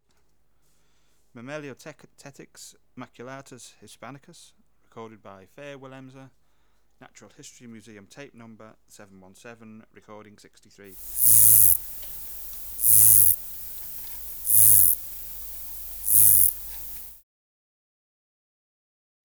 Natural History Museum Sound Archive Species: Myrmeleotettix maculatus hispanicus
Recording Location: Room in private house. Eygelshoven, Netherlands.
Substrate/Cage: In cage
Microphone & Power Supply: AKG D202 (LF circuit off) Distance from Subject (cm): 10